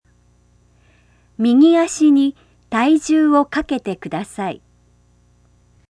例文
スタッフが患者/利用者に